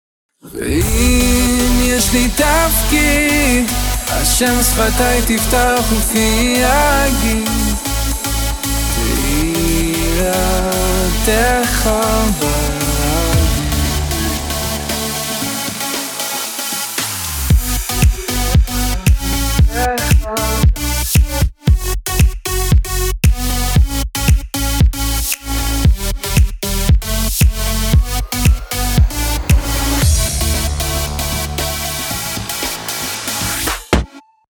זה קצת יבש מידי.